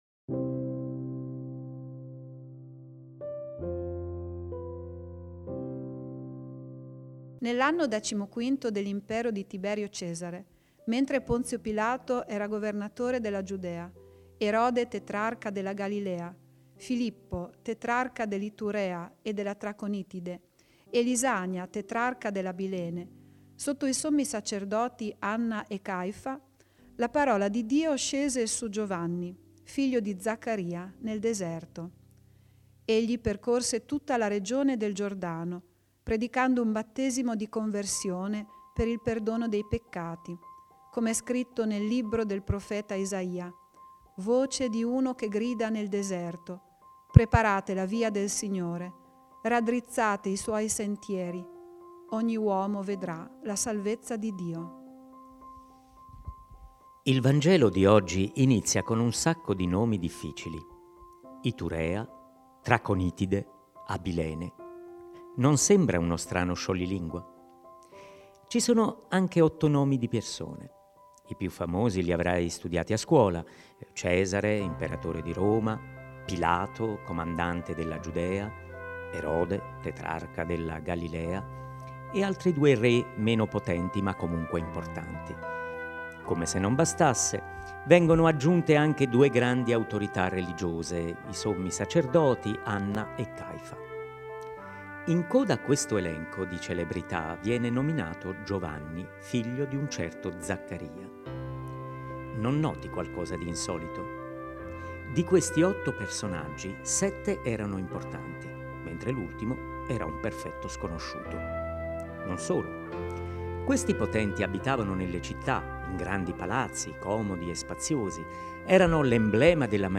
Ogni uomo vedrà la salvezza di Dio La riflessione guidata dall’arcivescovo di Modena-Nonantola e Vescovo di Carpi don Erio Castellucci sul Vangelo di Luca (Lc 3, 1-6) nella seconda domenica di Avvento Scarica in formato mp3 –> FacebookPinterestTwitterLinkedinWhatsapp